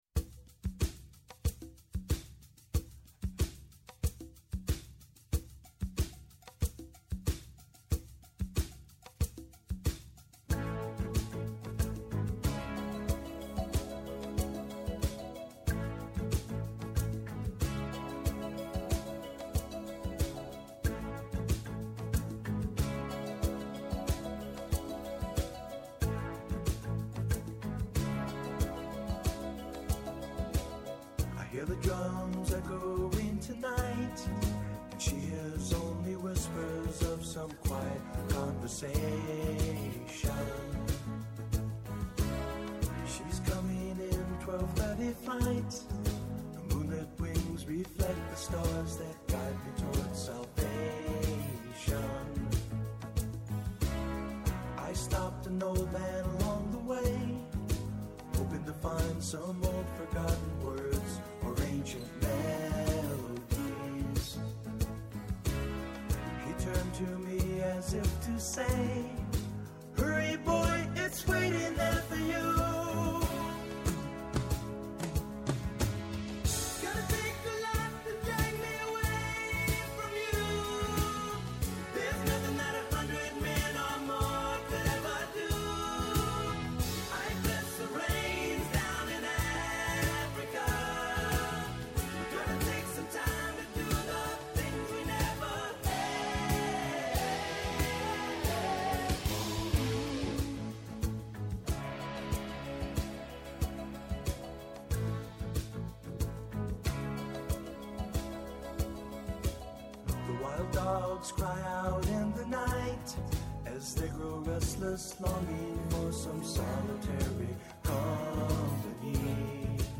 Καλεσμένος σήμερα ο Δήμαρχος Περιστερίου, Ανδρέας Παχατουρίδης.